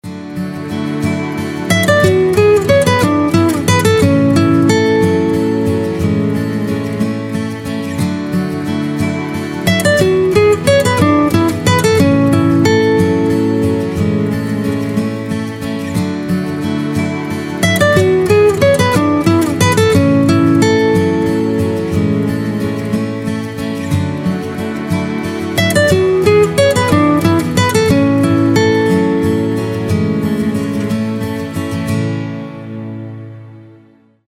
Рингтоны без слов
Рингтоны старый телефон , Инструментальные